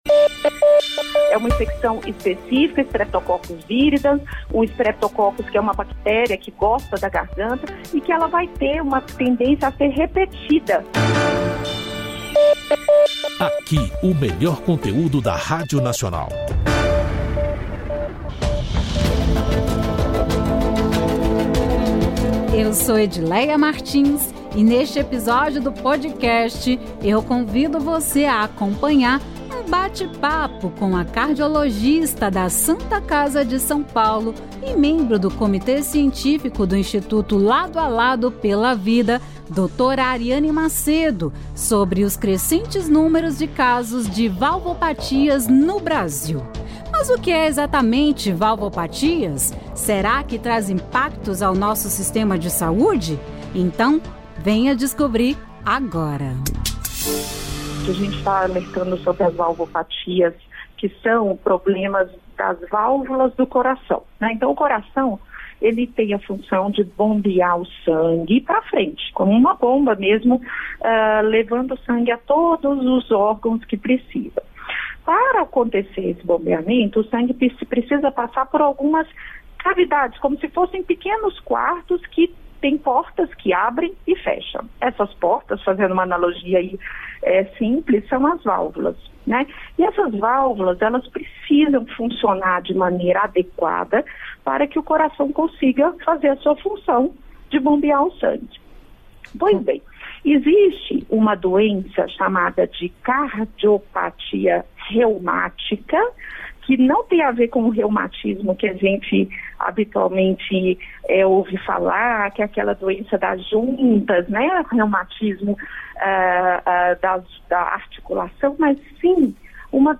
Podcast Entrevista é Nacional: infecções que podem gerar cardiopatias